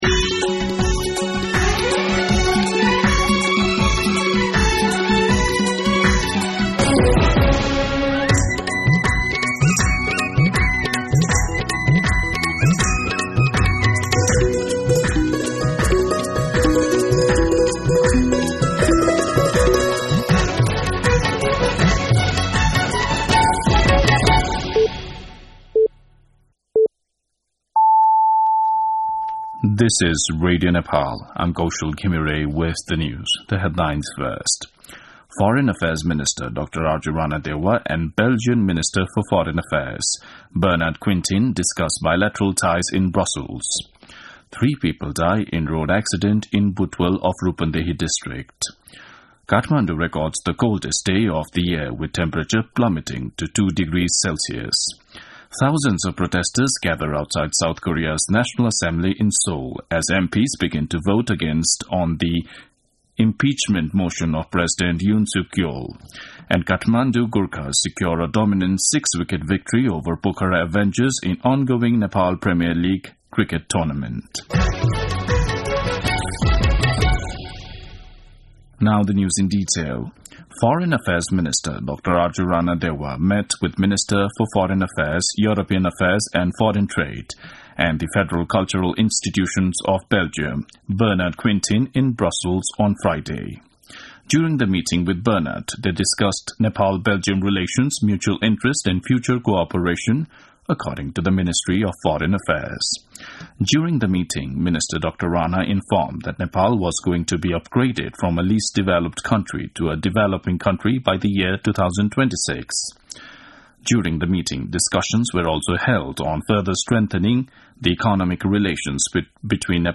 दिउँसो २ बजेको अङ्ग्रेजी समाचार : ३० मंसिर , २०८१
2-pm-Englishi-News.mp3